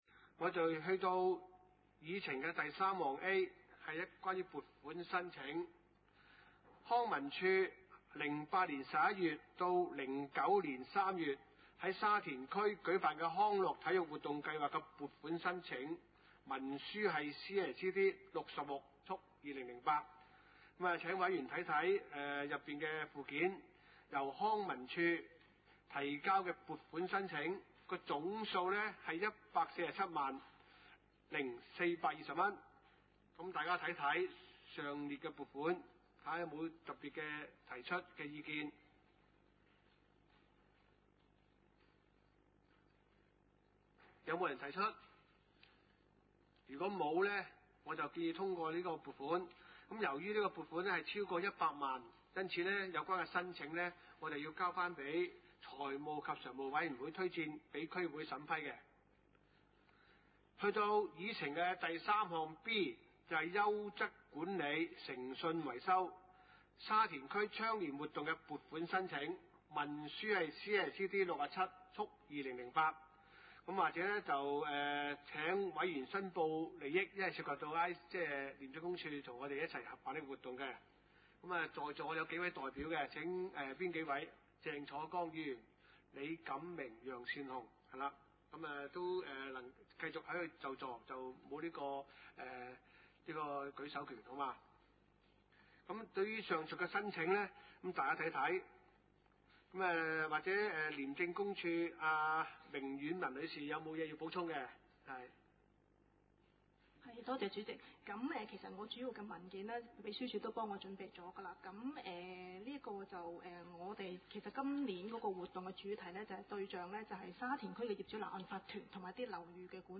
地點：沙田區議會會議室